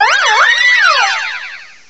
cry_not_diancie.aif